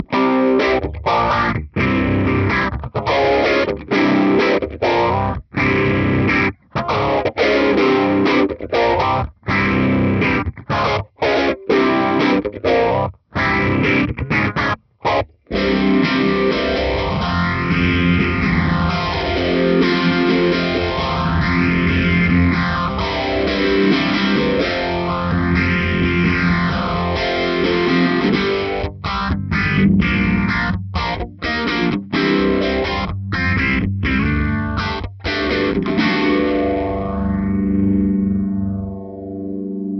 No EQ, no compression, no reverb, just the guitar plugged into the audio interface.
We are using virtual SHOD (Sweet Honey Overdrive, licensed by Mad Professor) for the dirty tone wich is such a great pedal for rock music.
In the first part of the audio sample you hear the Deluxe1 and in the second part you hear the TwinR.
Crunch Tone
P90 (phaser)
Crunch-P90.mp3